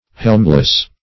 Search Result for " helmless" : The Collaborative International Dictionary of English v.0.48: Helmless \Helm"less\, a. 1.